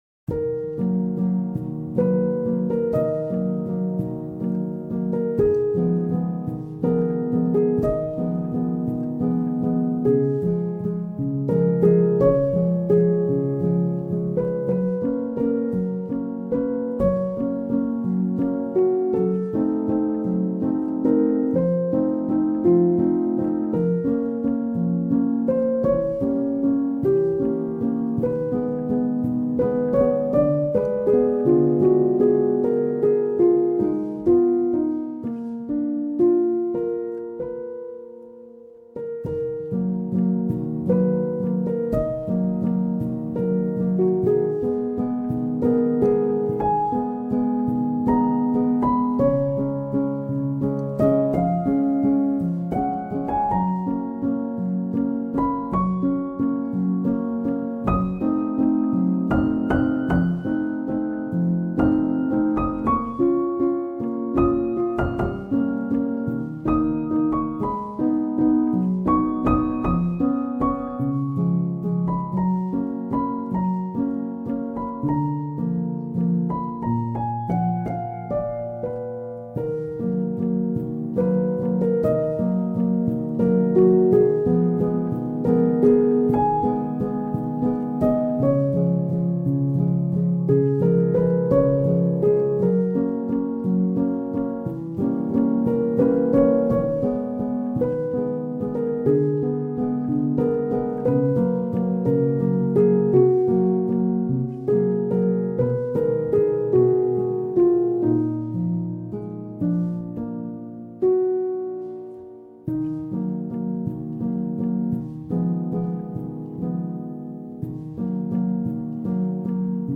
آرامش بخش , الهام‌بخش , پیانو , مدرن کلاسیک , موسیقی بی کلام